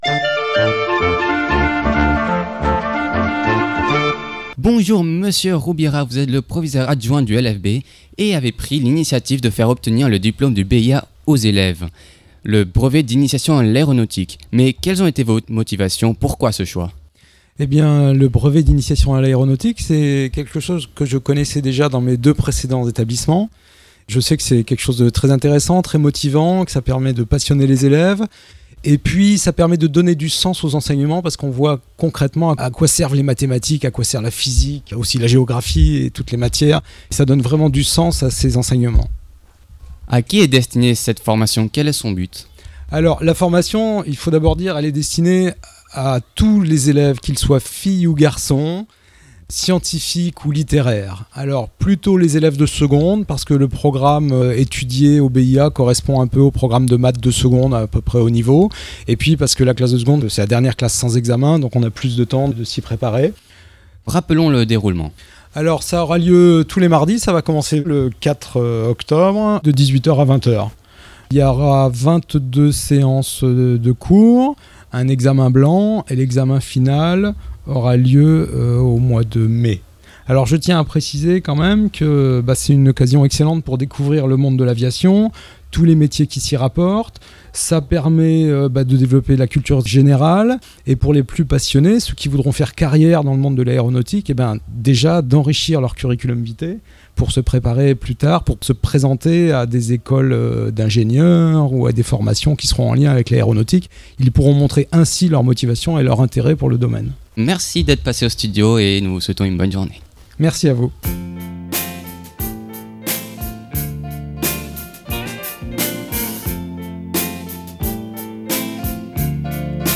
04/10/2016 à 10h 25| 2 mn | interview| lycée | événement |télécharger